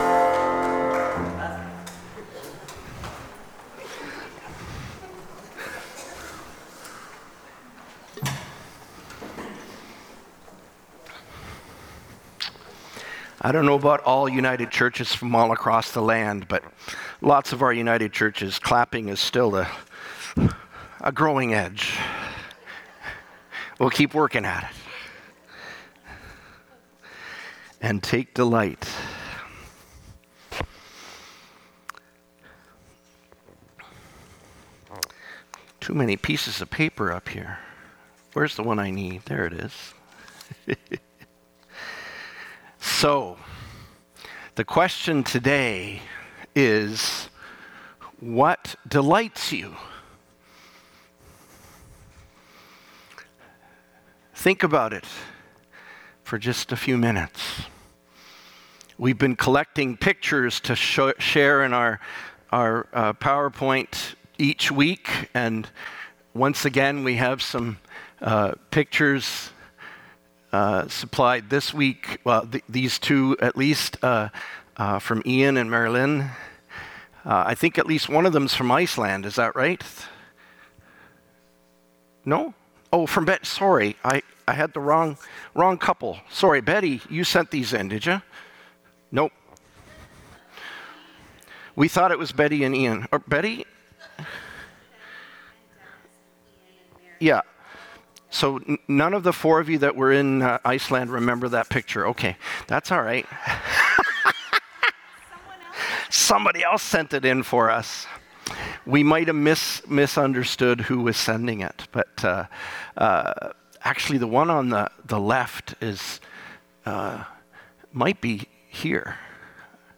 Sermons | Gilmore Park United Church
Aug. 3rd 2025 Reflection